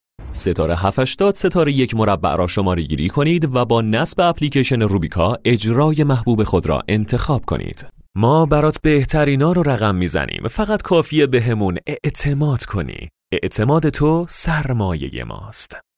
Male
Young
Adult
Now, he has his own home studio and can energetically read and record any type of script you can imagine.
Commercial-03